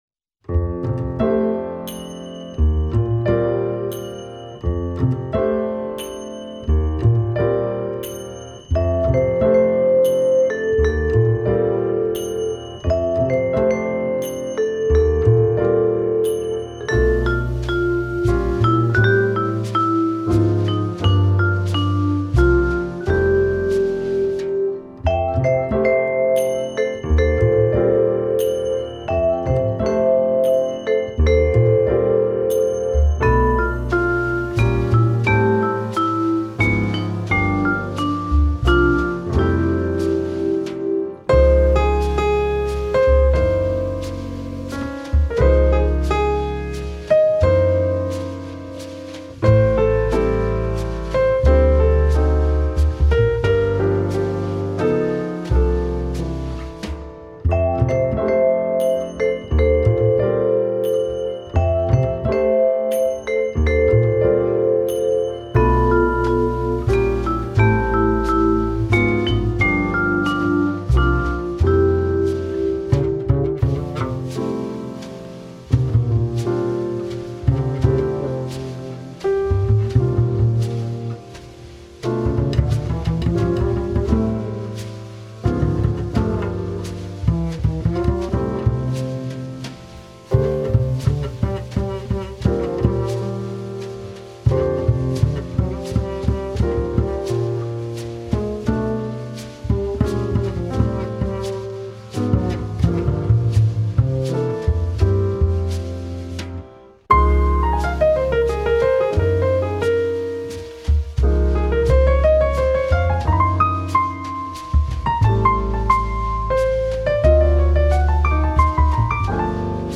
Brass quintet with percussion.